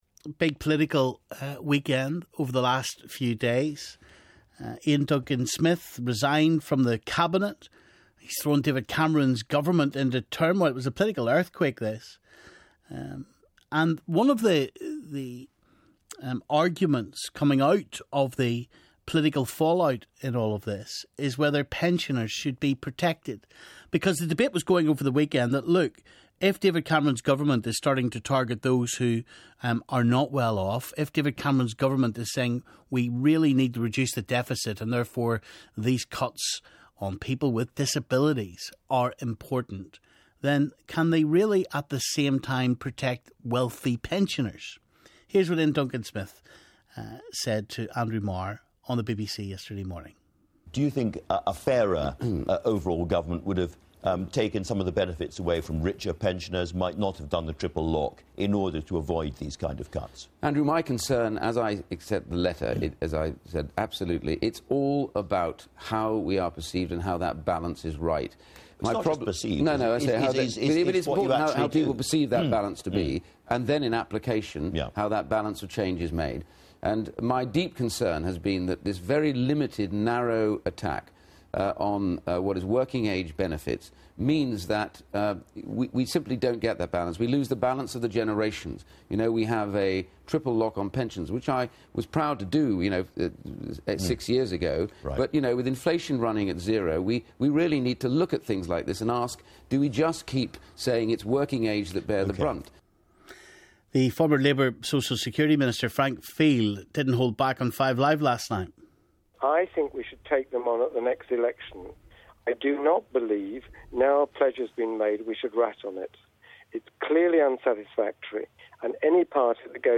Now on the Nolan Show, the big pensioner benefits debate. When cuts are biting elsewhere, should pensions keep going up? And should other pensioner benefits like bus passes, winter fuel allowance and free TV licences still be protected?